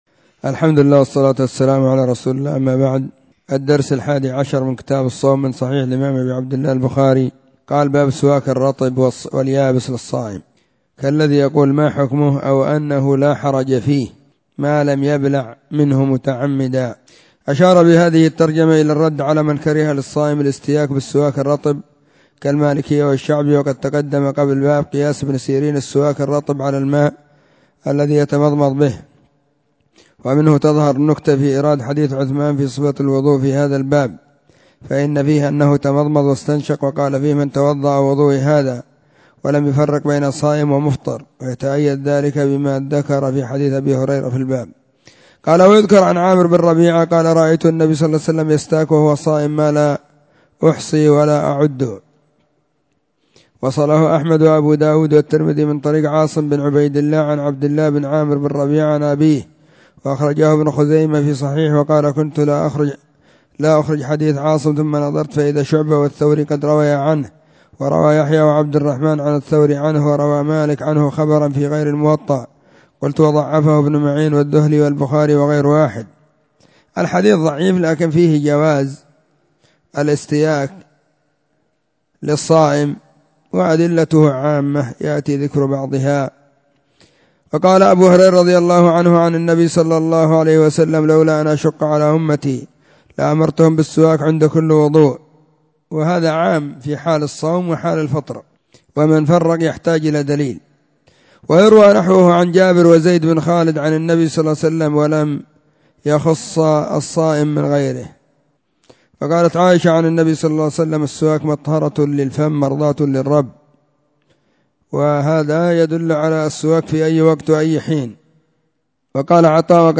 🕐 [بين مغرب وعشاء – الدرس الثاني]
🕐 [بين مغرب وعشاء – الدرس الثاني] 📢 مسجد الصحابة – بالغيضة – المهرة، اليمن حرسها الله.